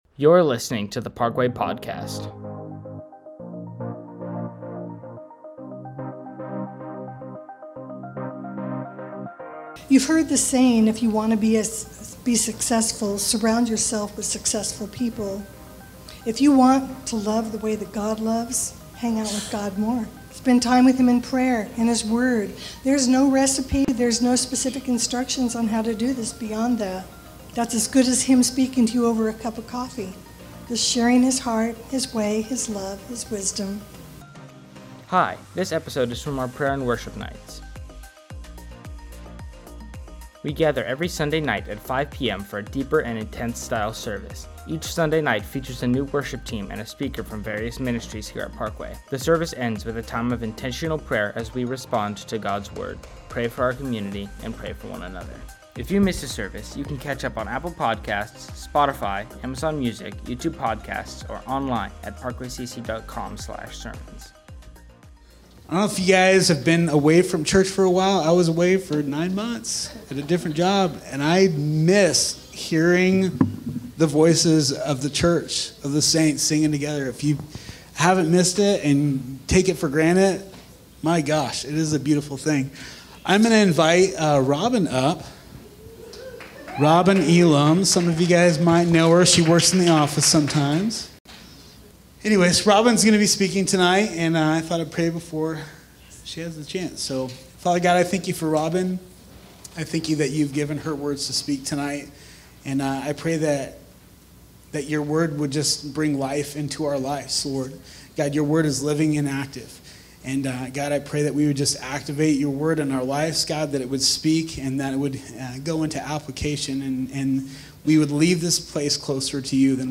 A message from the series "Prayer & Worship Nights."